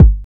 Metro Kicks [OG].wav